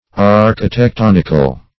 \Ar`chi*tec*ton"ic*al\, a. [L. architectonicus, Gr.